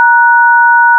Captions English A DTMF Pound Tone
DtmfPound.ogg